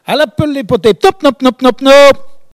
Elle crie pour appeler les canards